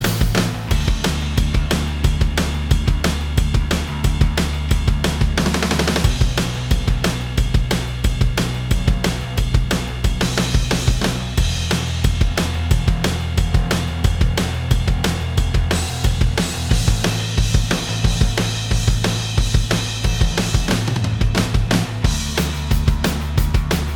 Minus All Guitars Indie / Alternative 3:09 Buy £1.50